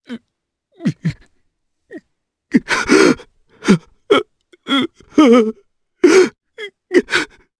Clause_ice-Vox_Sad_jp.wav